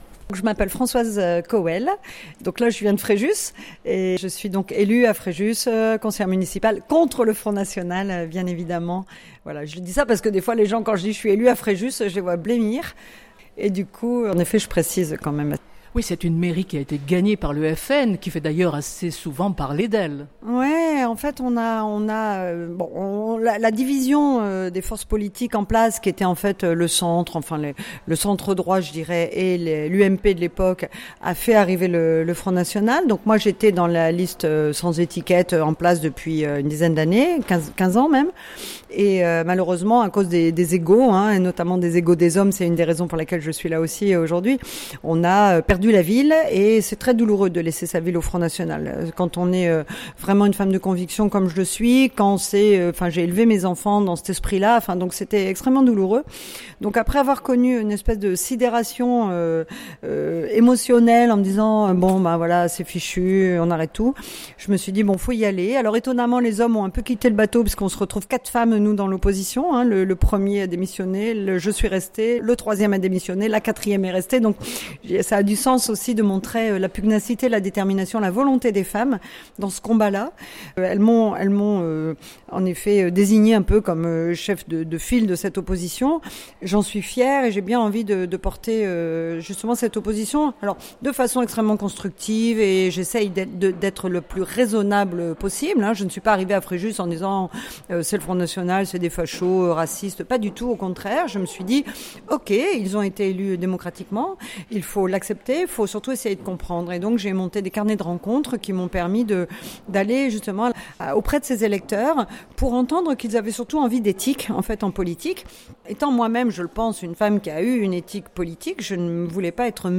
Entretiens